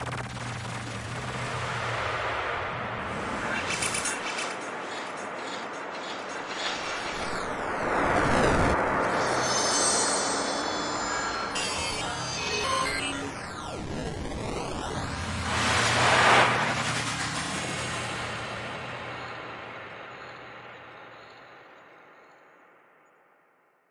Tag: 电子 数字 处理 声音设计 样本 抽象的 混音